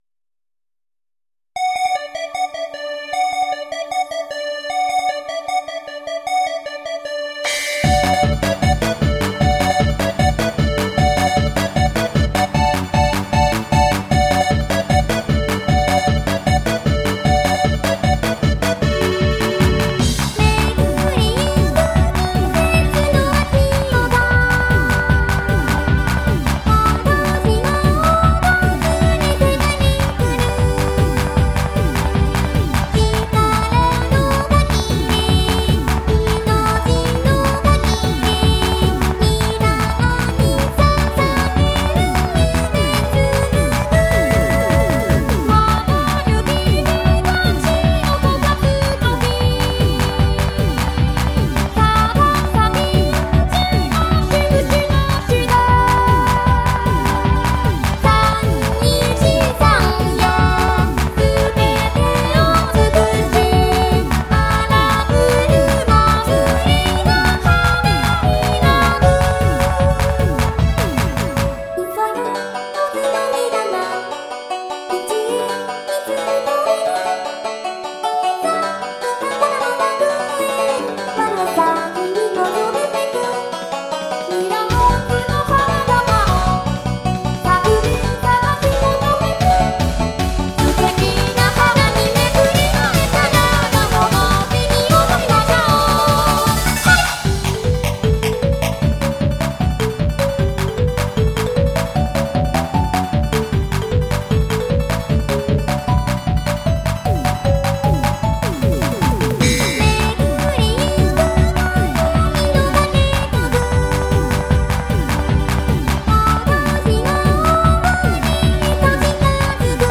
・オリジナルボーカロイドシングル